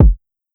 Bricks Kick.wav